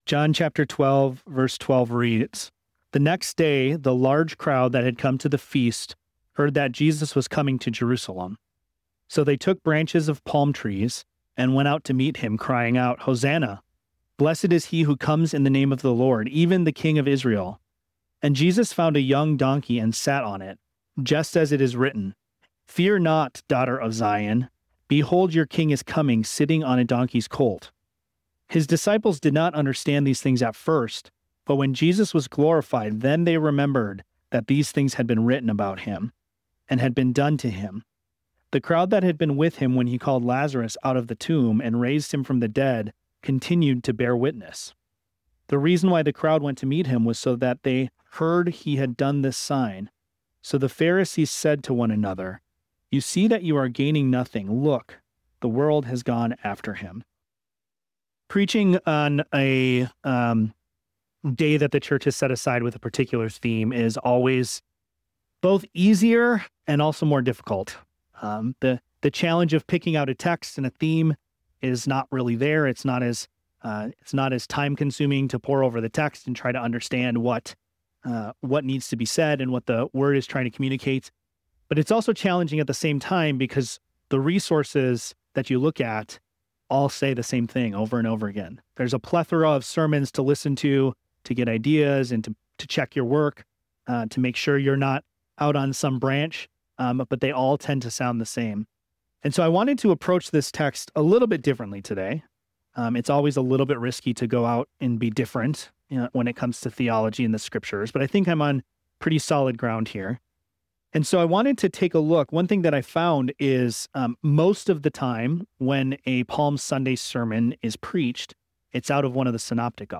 The sermon concludes with a message of hope for believers, emphasizing that Christ’s victory on Palm Sunday points forward to His final victory at the end of history.